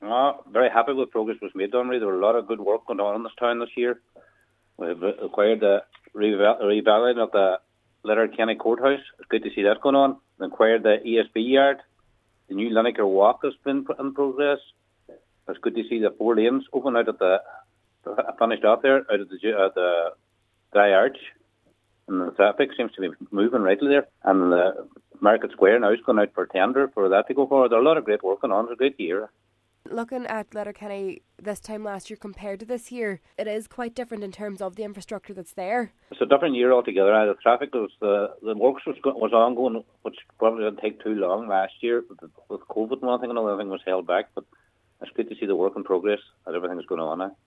Cllr Kevin Bradley says he feels its been a good run to get projects finished post-COVID: